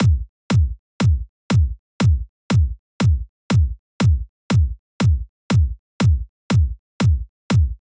23 Kick.wav